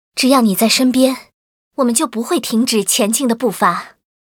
文件 文件历史 文件用途 全域文件用途 Erze_tk_05.ogg （Ogg Vorbis声音文件，长度4.3秒，94 kbps，文件大小：50 KB） 源地址:地下城与勇士游戏语音 文件历史 点击某个日期/时间查看对应时刻的文件。